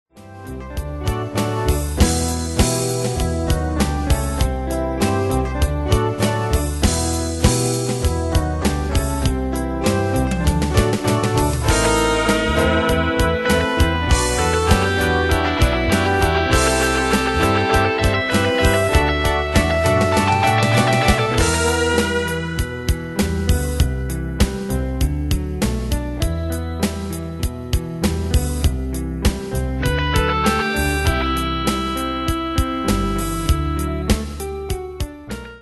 Style: Rock Année/Year: 1971 Tempo: 99 Durée/Time: 3.03
Pro Backing Tracks